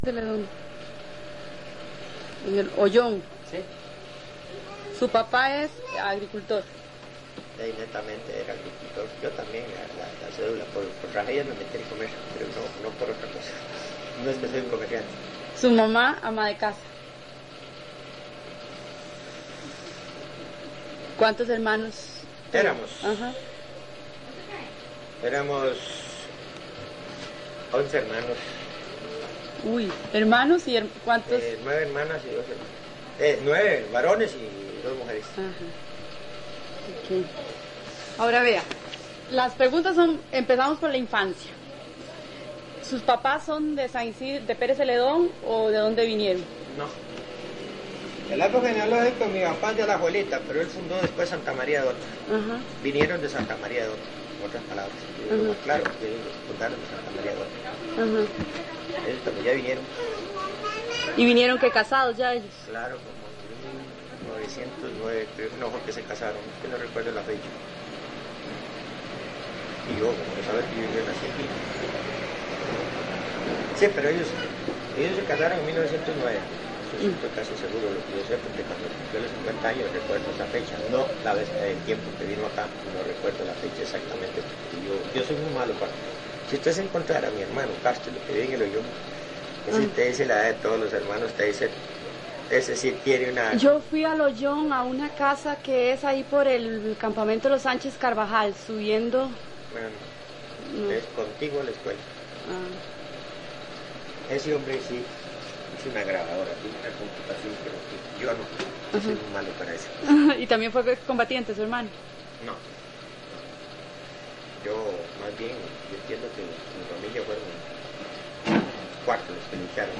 Entrevista
Notas: Casete de audio y digital